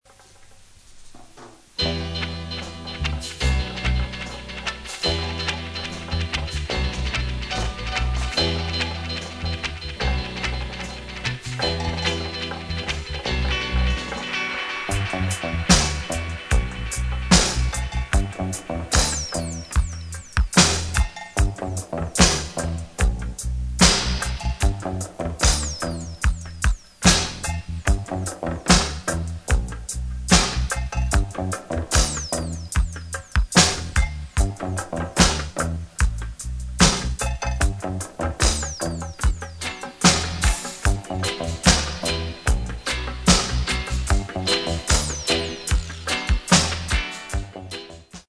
Recorded: Ariwa Studio